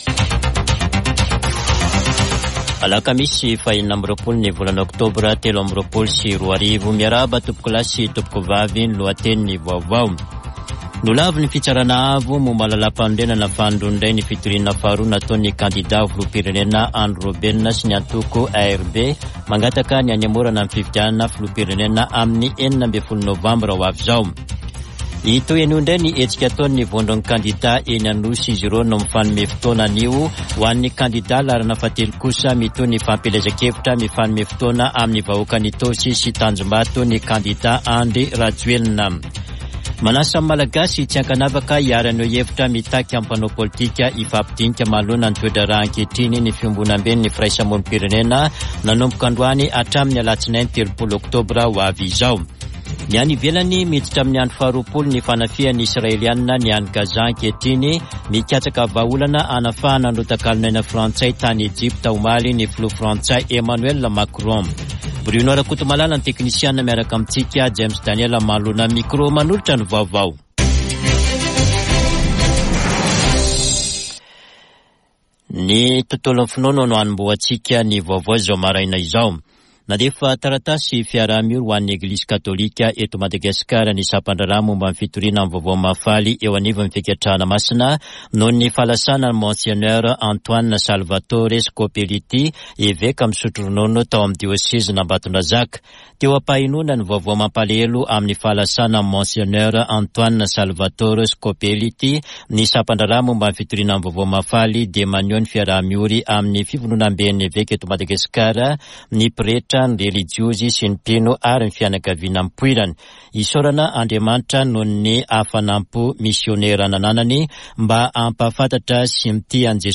[Vaovao maraina] Alakamisy 26 ôktôbra 2023